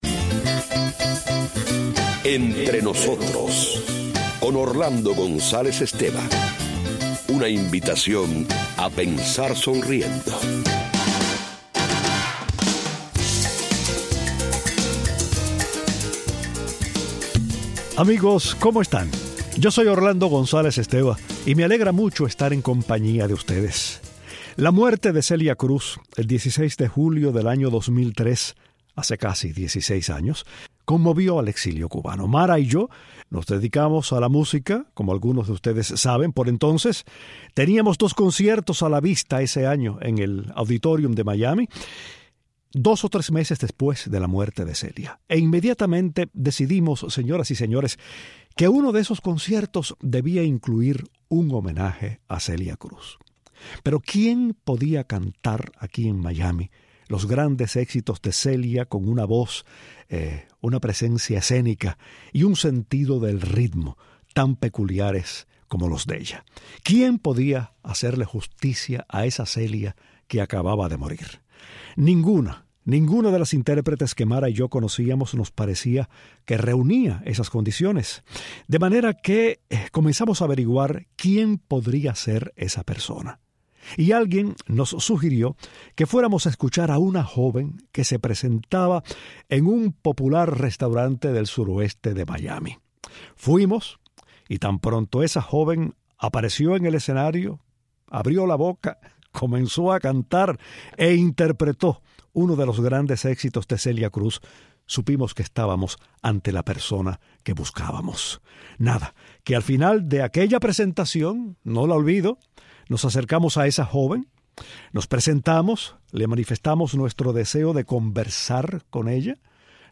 La excelente cantante cubana, nos visita